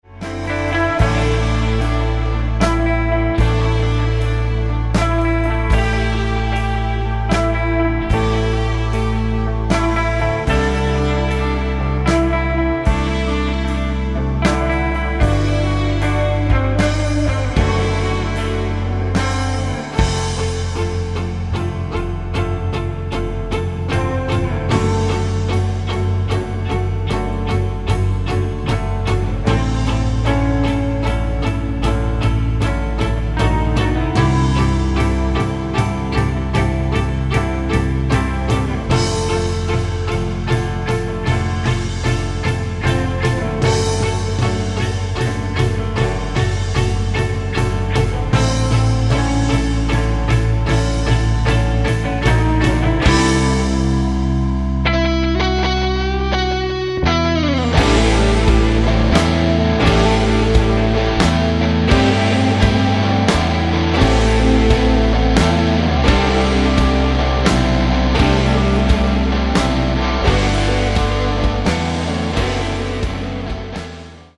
Каталог -> Электроакустические опыты -> Пост-рок